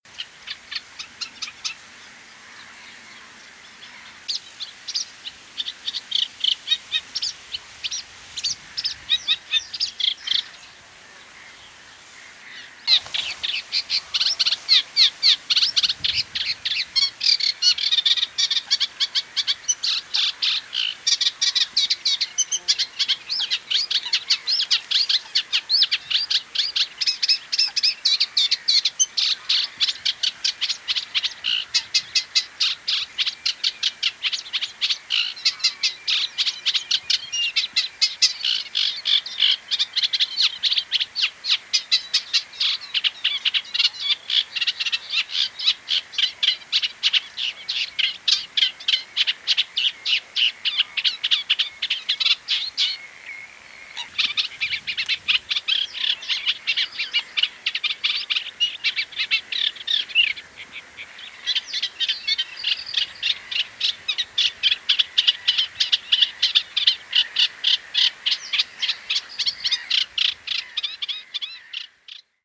kleine karekiet
🇬🇧 English: reed warbler
🔭 Wetenschappelijk: Acrocephalus scirpaceus
kleine_karekiet_zang_2018.mp3